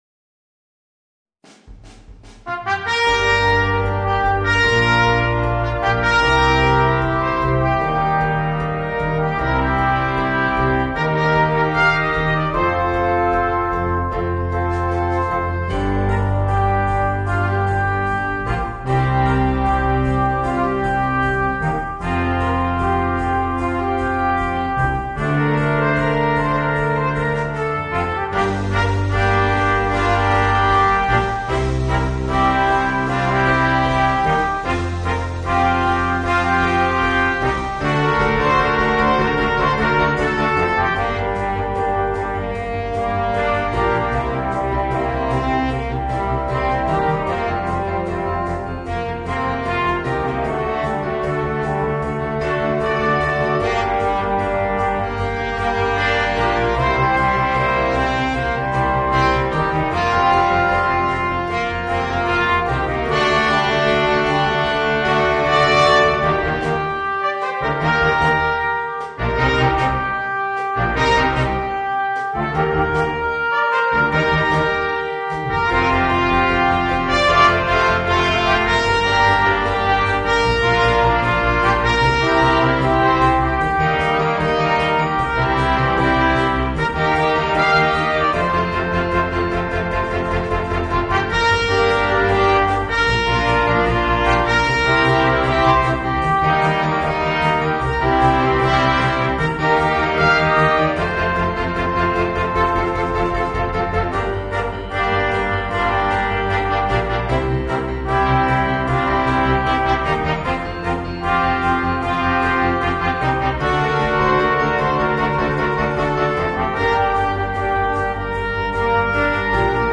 Voicing: 5 - Part Ensemble and Rhythm Section